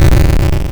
Explosion2.wav